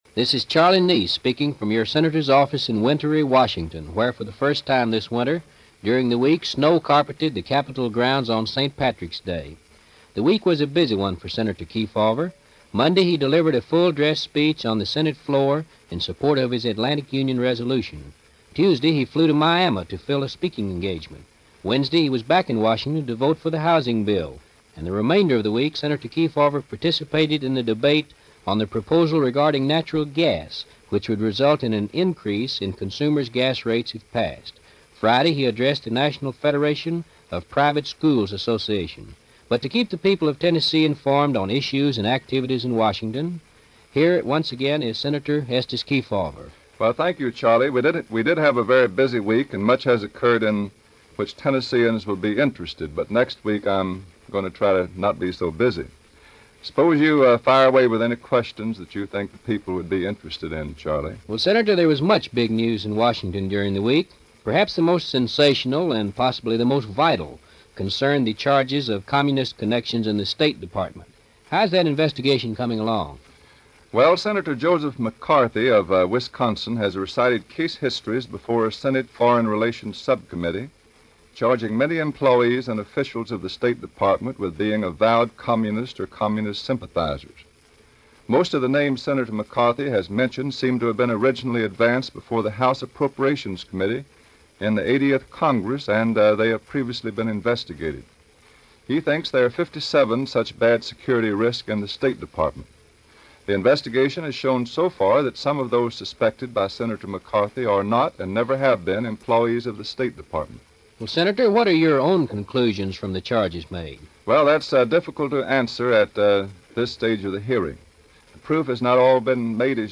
An Interview With Estes Kefauver - 1950 - Past Daily After Hours Reference Room